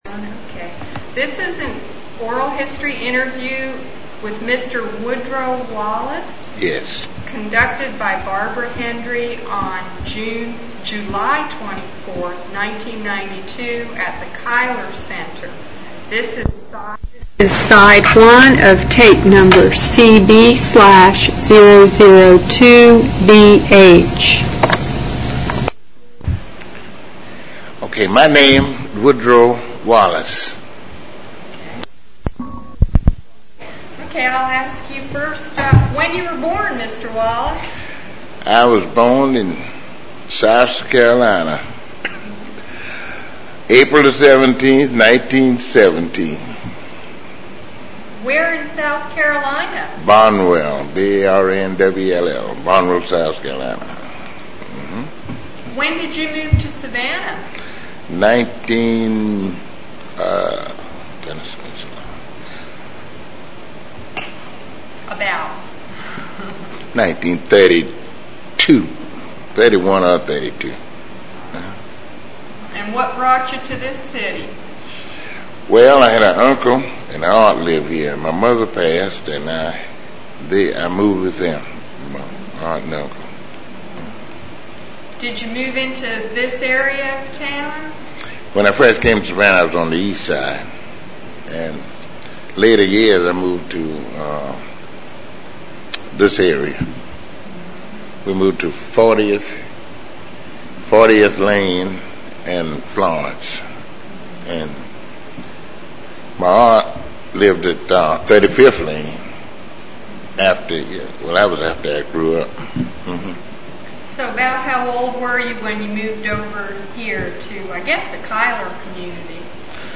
Interview
Object Name Tape, Magentic Credit line Courtesy of City of Savannah Municipal Archives Copyright Requests to publish must be submitted in writing to Municipal Archives.